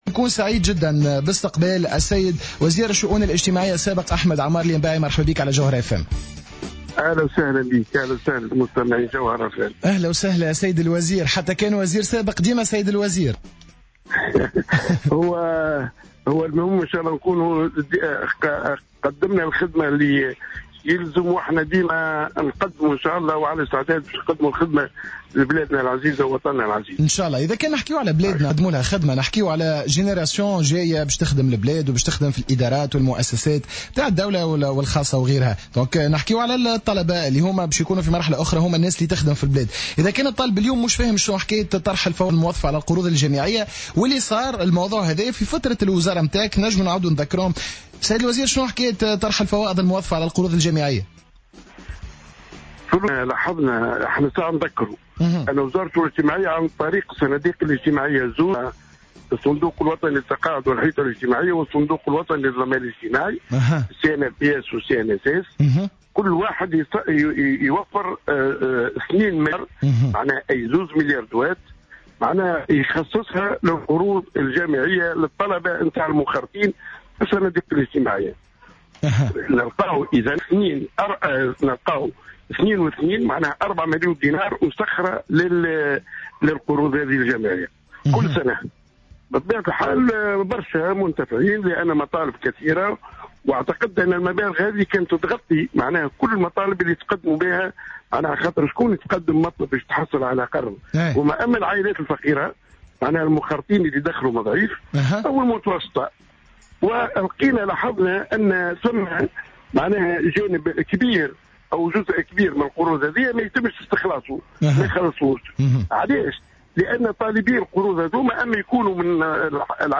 وأفاد في تصريح لـ "الجوهرة أف أم" في برنامج "هابي دايز" صباح اليوم، انه كان تقدم بمشروع لطرح الفوائض الموظفة على القروض الجامعية او التقليص من تكلفتها، وقد وافق مجلس الوزراء على هذا المشروع إلا أنه لم يصدر إلى حد الآن أمر حكومي لتفعيله.